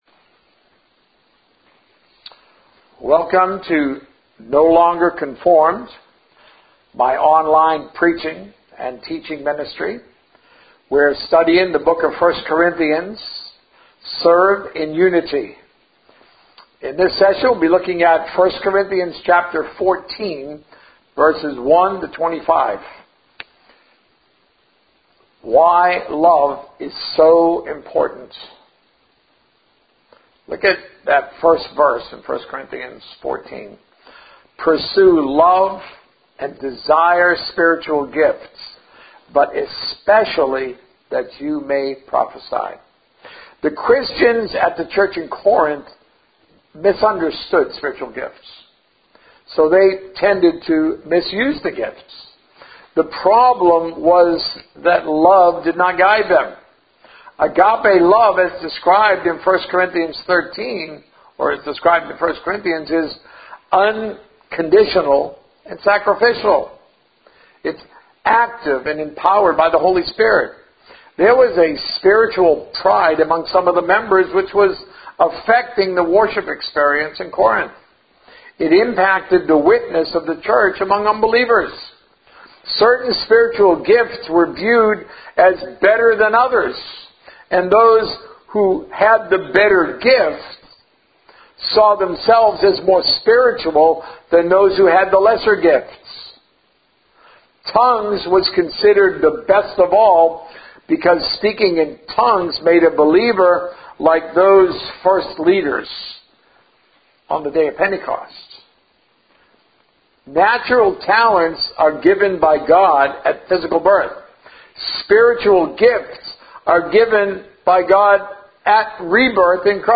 A message from the series "It's About Loving God."